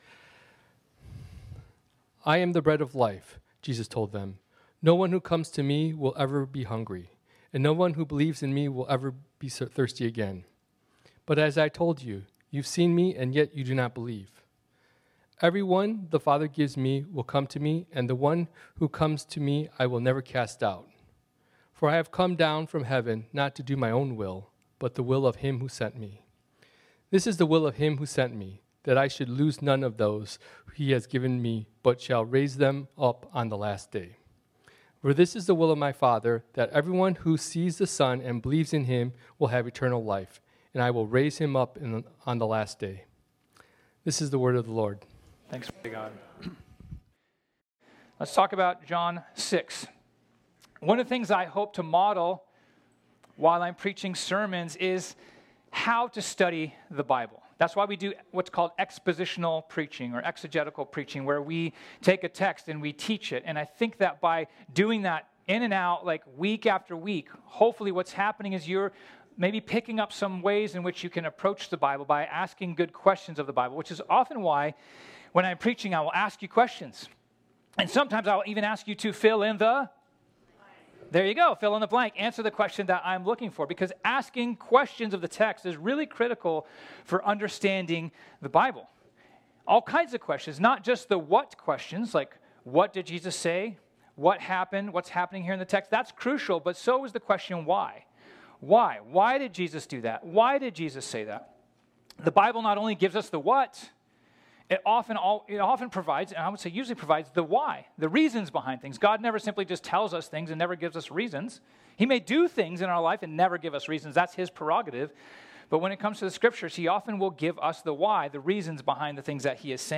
This sermon was originally preached on Sunday, December 8, 2024.